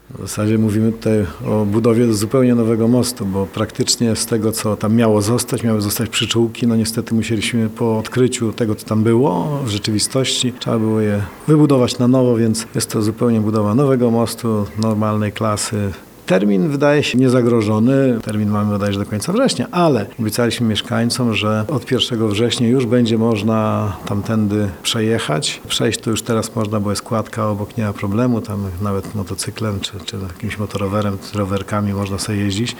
starosta-most.mp3